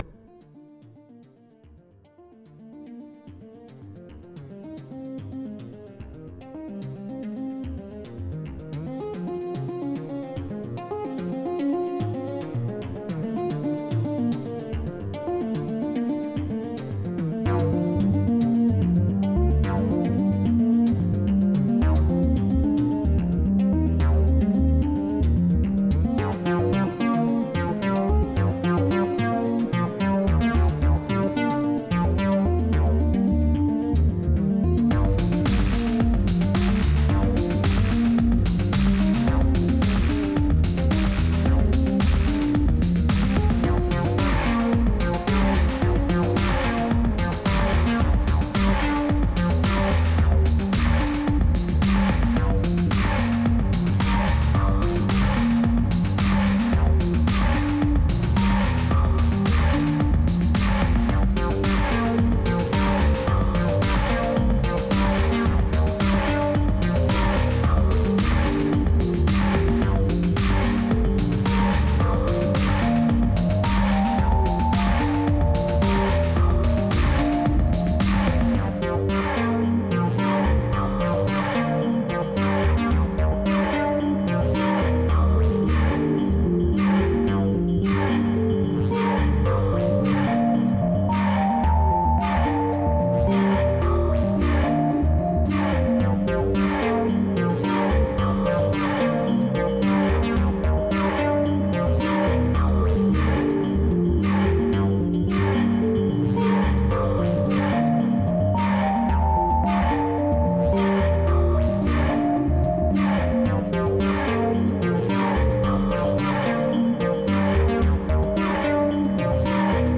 ・ボーカル無しの場合
・MONARKでは、FXトラックとBassトラックのクリップを作成（FXはイントロとエンディングに使用しフェードイン/フェードアウト）
・各トラックのパンを少し左右に振分け